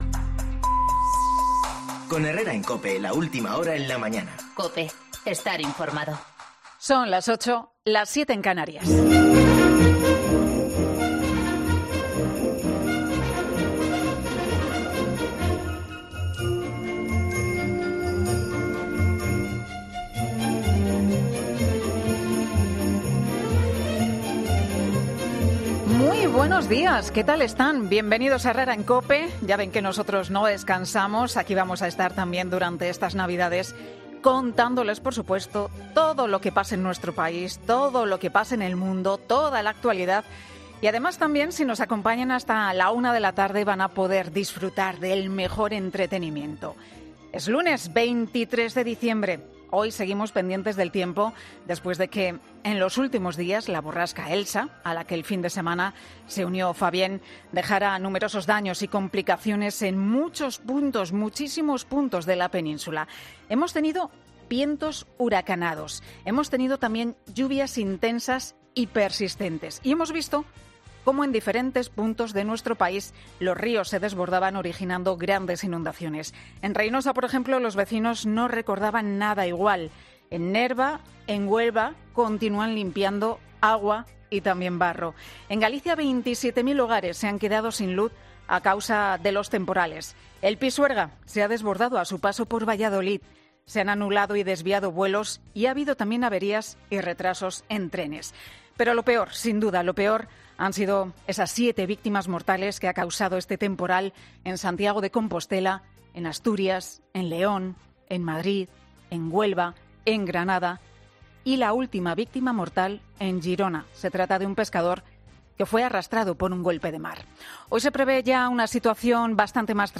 Escucha el monólogo de Pilar García Muñiz del 23 de diciembre de 2019